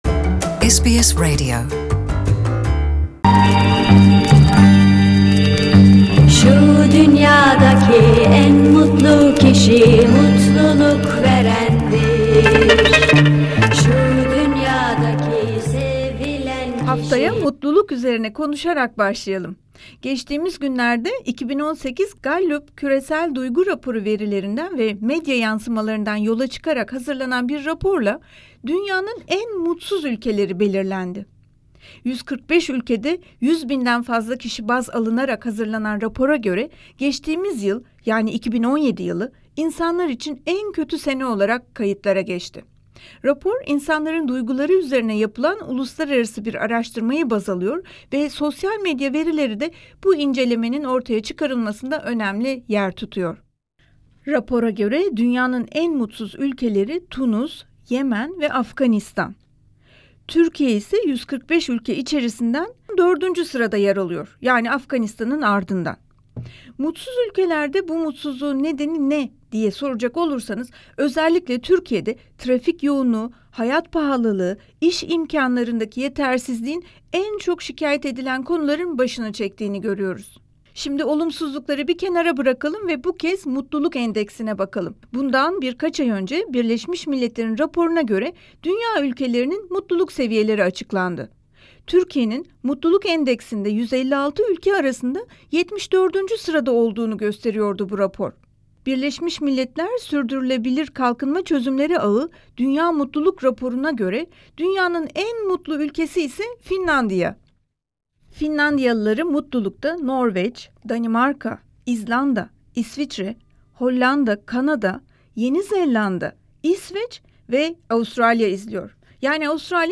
Avustralyalı Türk toplumuna bunu ve burada yaşamaktan mutlu olup olmadıklarını sorduk. Bakın toplum bireylerimiz neler söyledi...